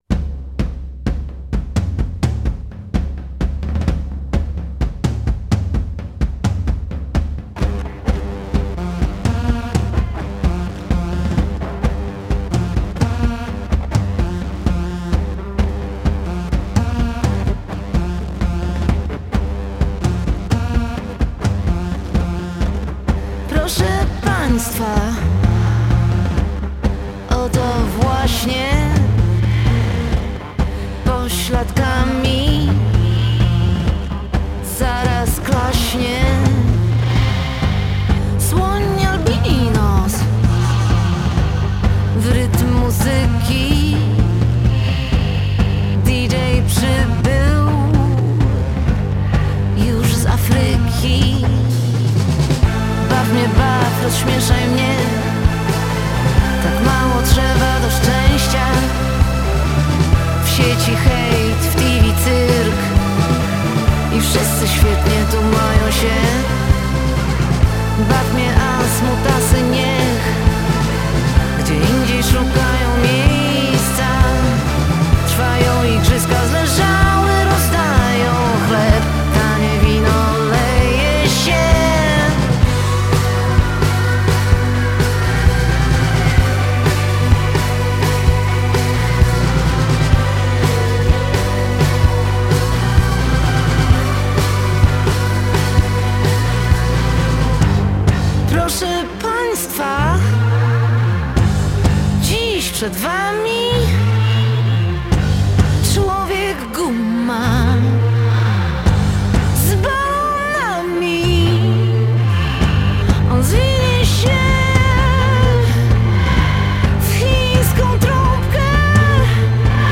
Z Edytą Bartosiewicz rozmawiał